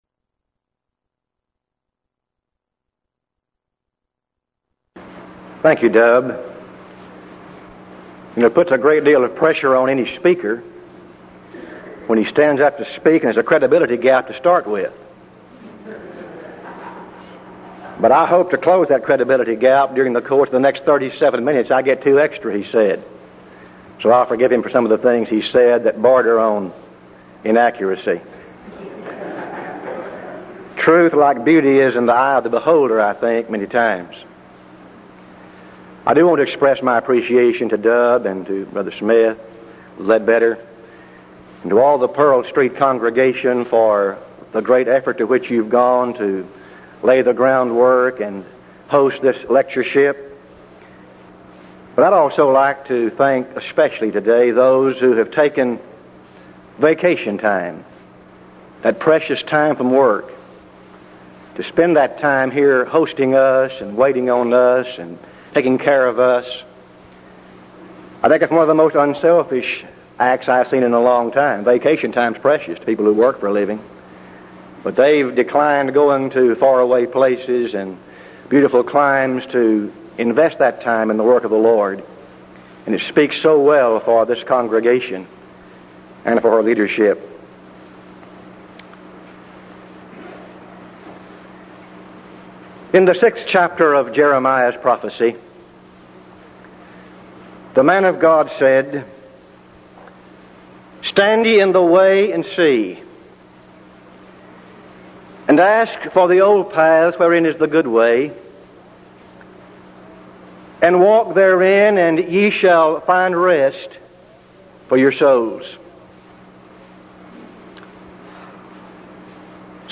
Event: 1983 Denton Lectures Theme/Title: Studies in Hebrews